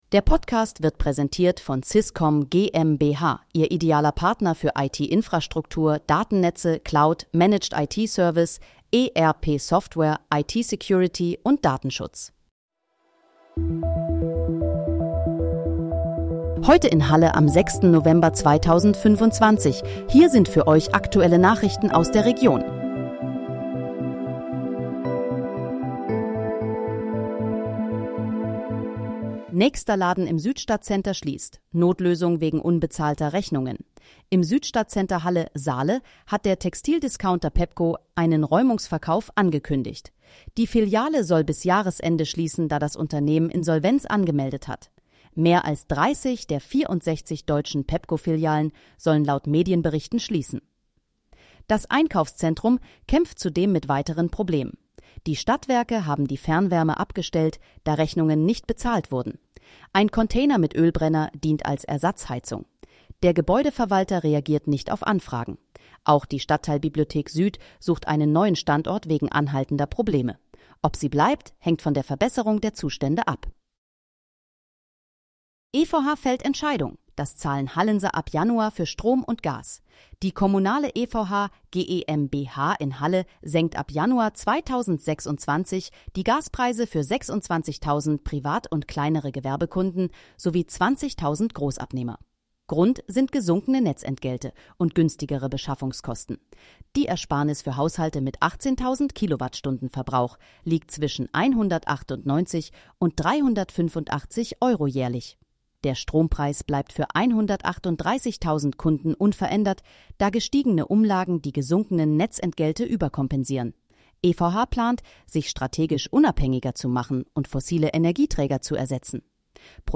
Heute in, Halle: Aktuelle Nachrichten vom 06.11.2025, erstellt mit KI-Unterstützung
Nachrichten